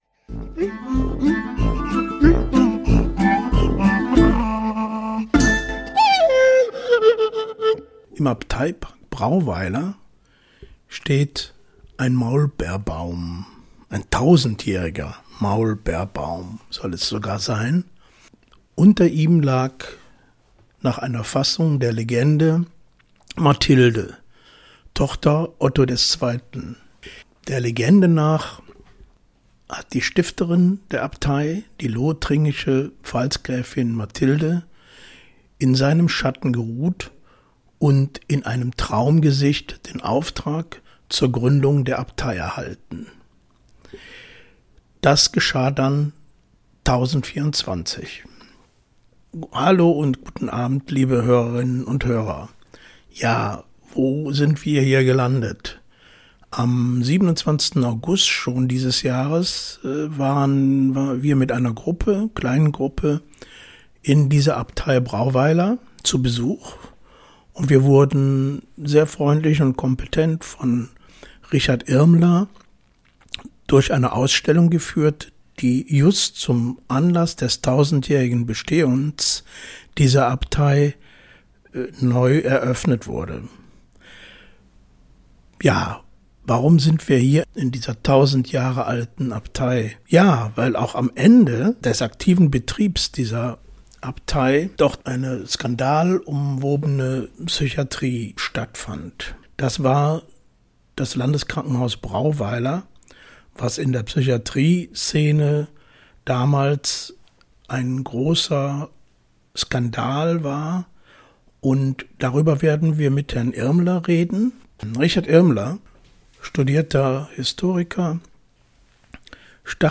Es kommen auch Zeitzeugen zu Wort, die dort gearbeitet haben.